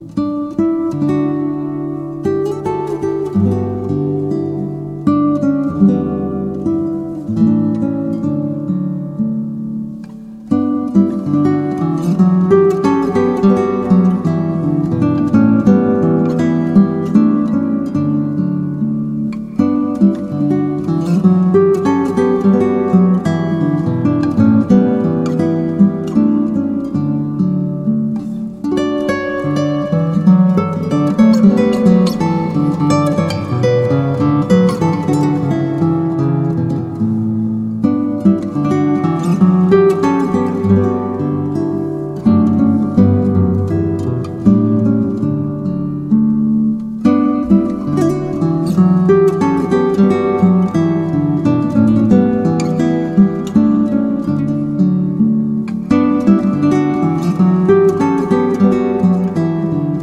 Instrumentalversionen beliebter Lobpreislieder
• Sachgebiet: Praise & Worship